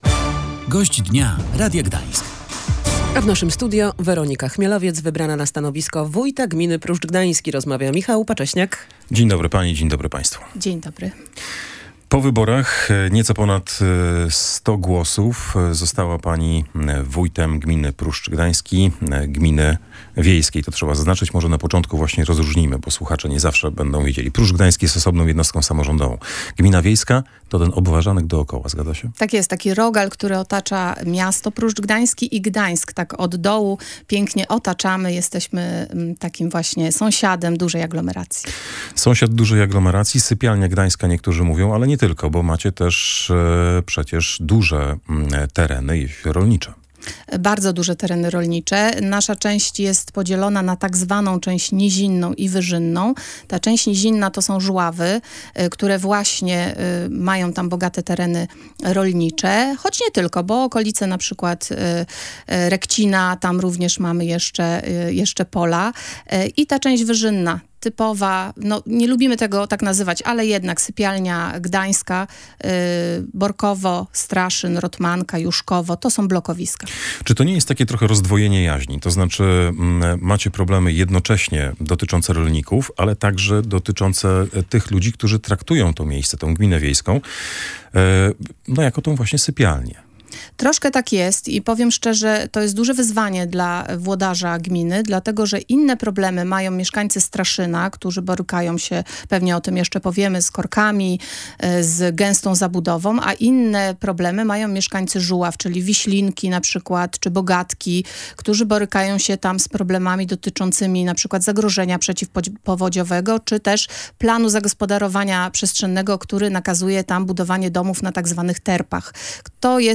O powyborczej rzeczywistości i przyszłości gminy Pruszcz Gdański opowiada Weronika Chmielowiec, wybrana na stanowisko wójta gminy.